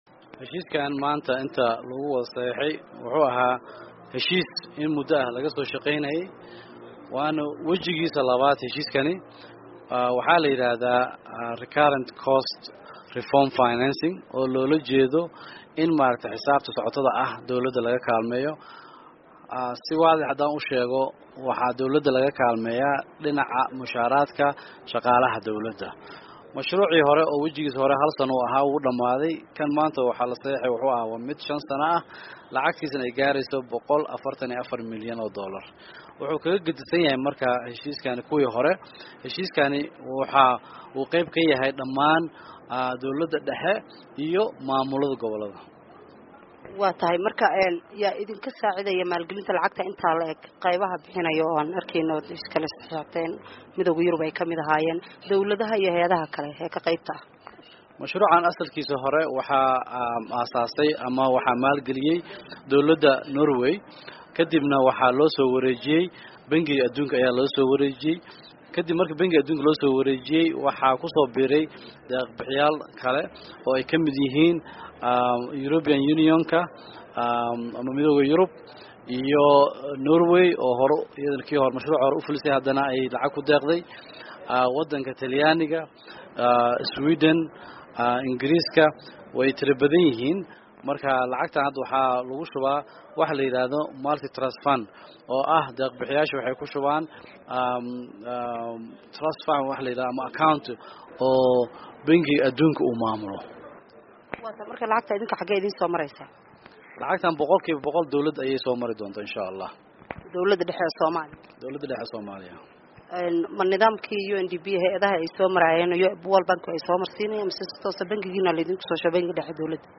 Dhageyso Wareysiga Wasiirka Maaliyadda